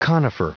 Prononciation du mot conifer en anglais (fichier audio)
conifer.wav